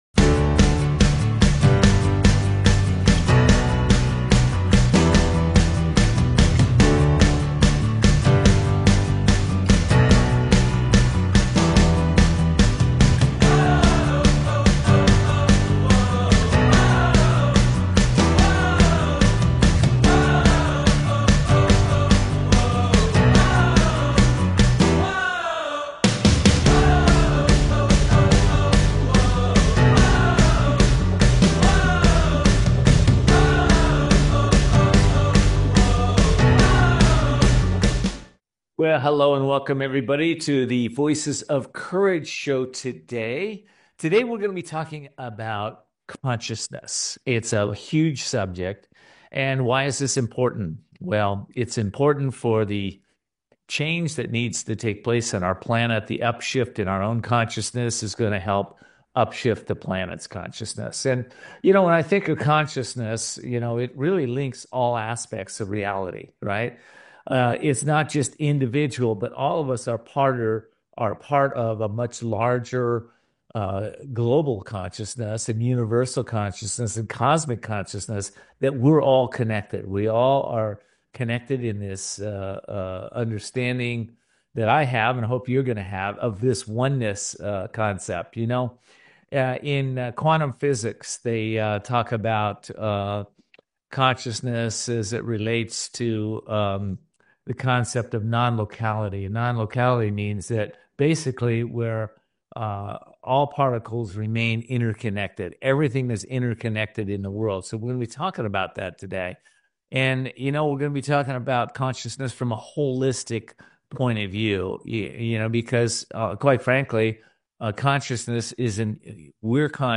Join us for an inspiring conversation with Ervin Laszlo, renowned philosopher, systems scientist, and author, as we dive into his latest book, The Great Upshift. In this episode, Ervin discusses the transformative power of global consciousness, the challenges facing humanity, and how we can collectively rise to create a sustainable and harmonious future.